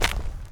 pedology_gravel_footstep.4.ogg